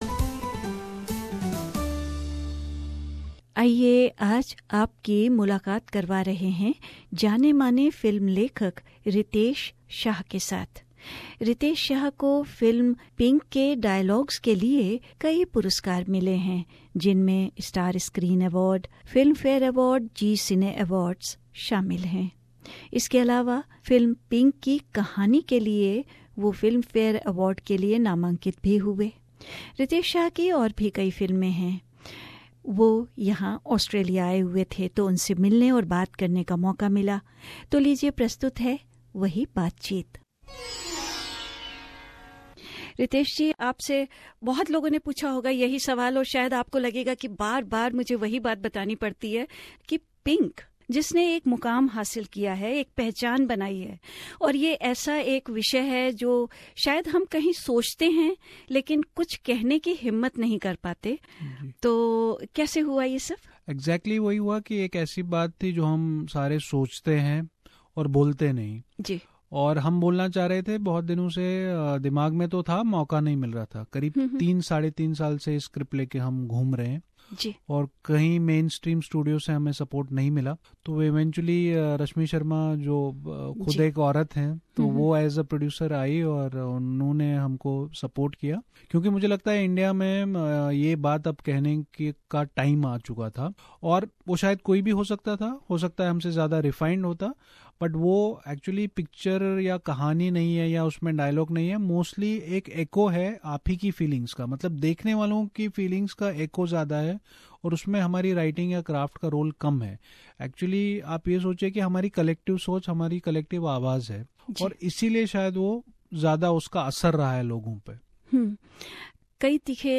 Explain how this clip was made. SBS Radio Studio, Melbourne, Australia